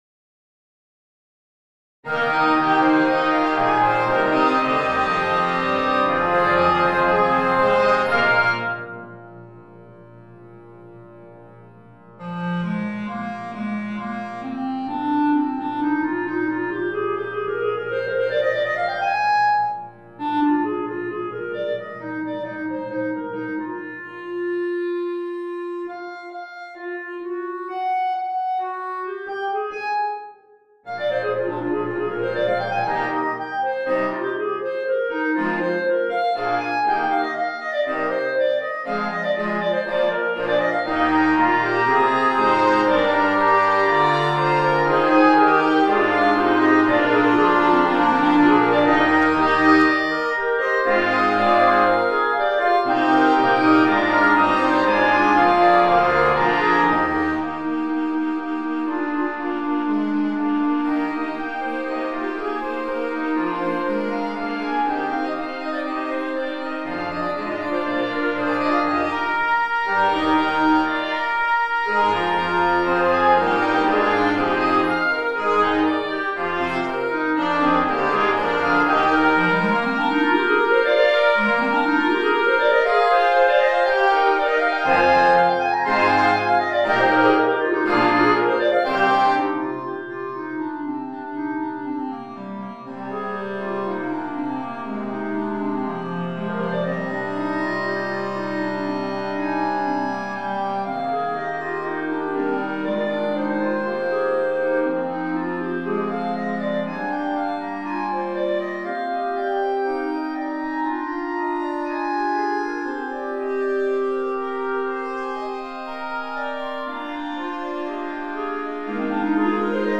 Concert Band Grade 4